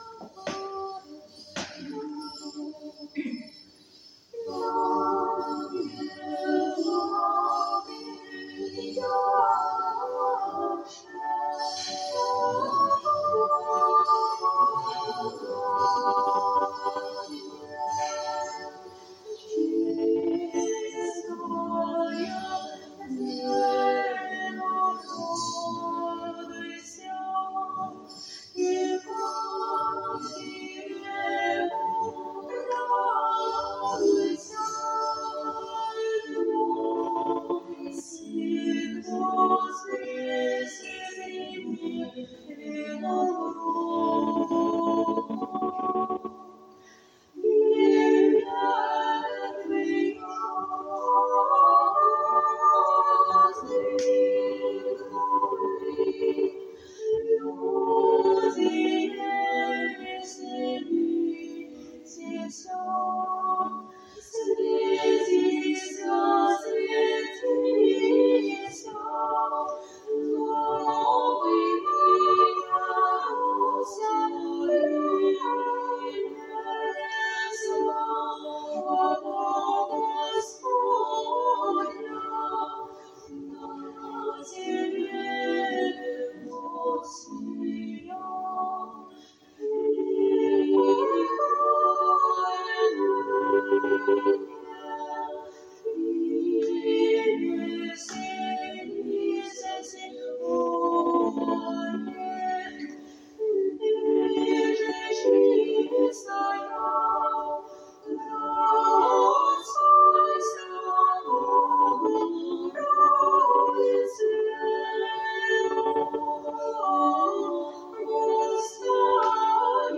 Престольный праздник 21.05.2022 Вассербург
Хор